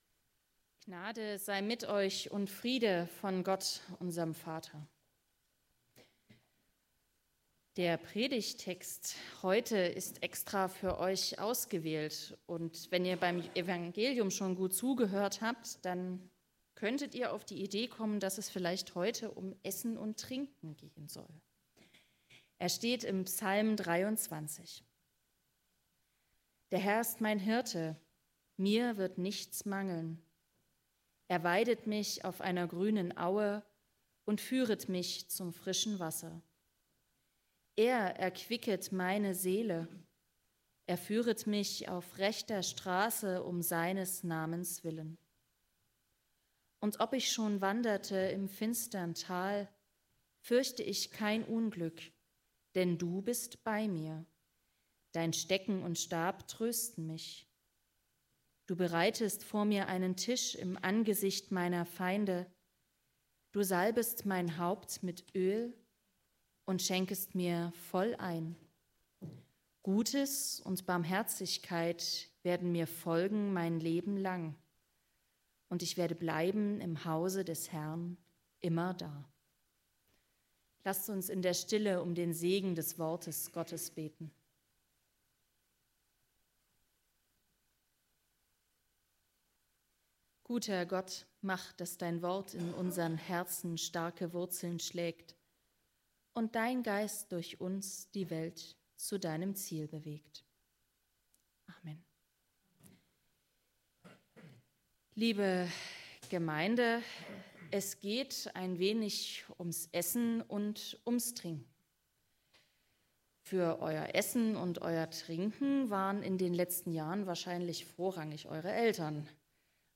Die heutige Predigt ist auf unsere Konfis zugeschnitten. Es geht um das Thema Essen und Trinken.